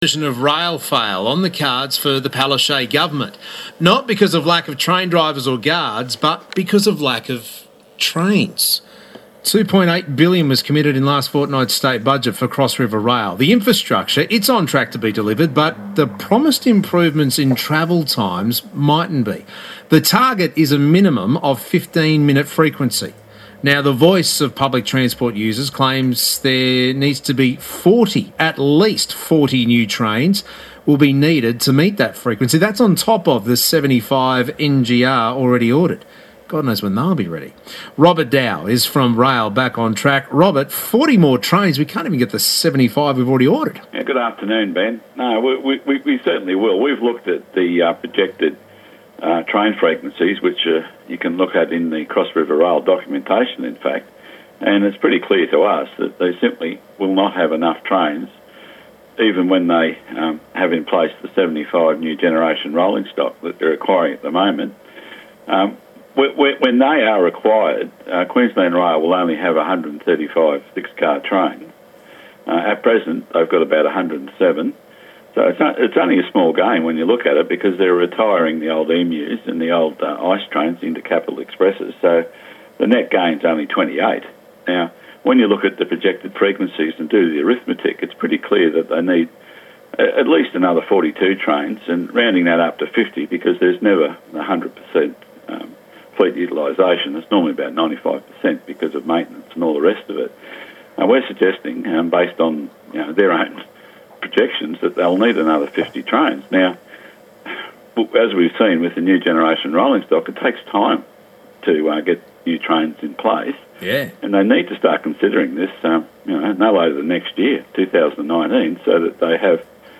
Here is the interview: